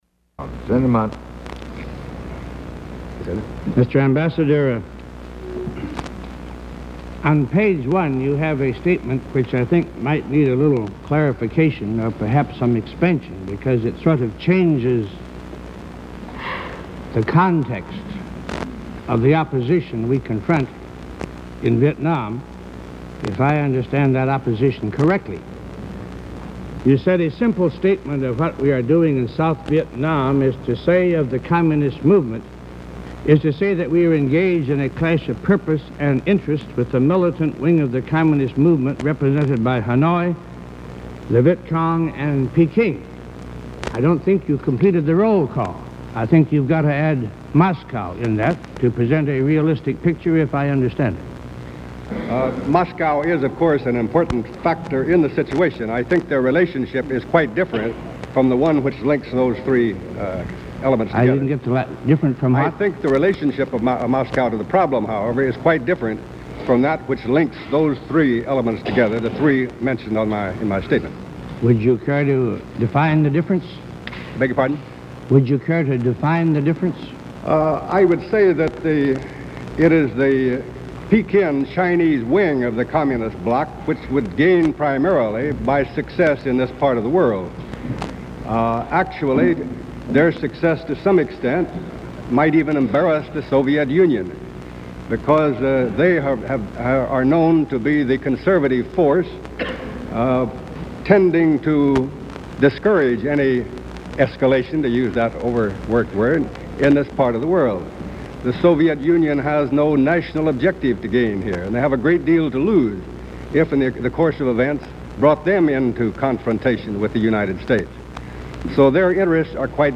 United States Senate hearing on the Vietnam War. Part 5